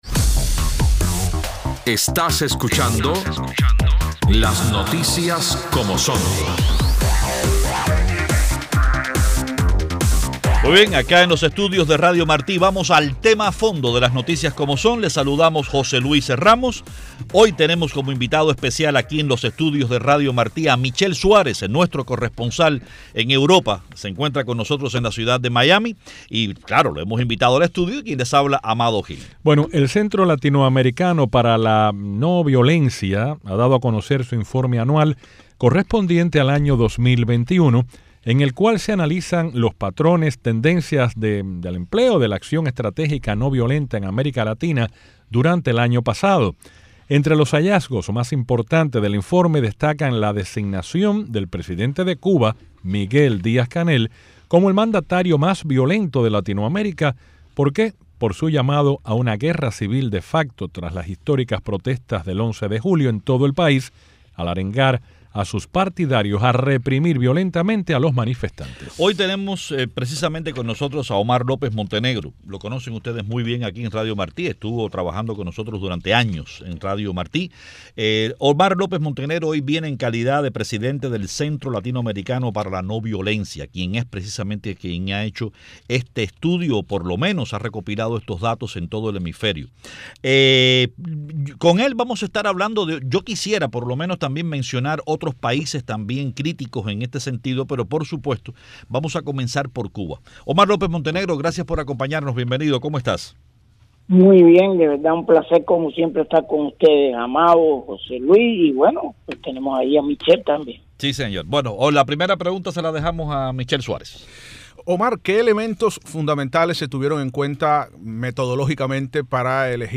Entrevista sobre el informe anual en el programa “Las noticias como son” de Radio Marti.